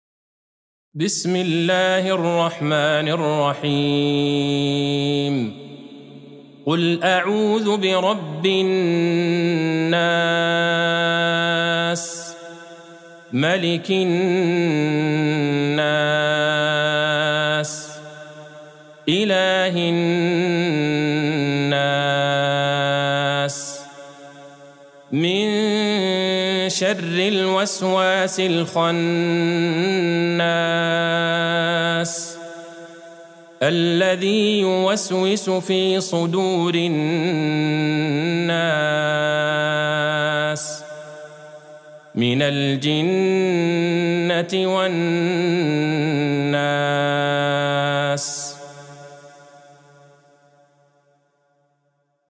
سورة الناس Surat An-Nas | مصحف المقارئ القرآنية > الختمة المرتلة ( مصحف المقارئ القرآنية) للشيخ عبدالله البعيجان > المصحف - تلاوات الحرمين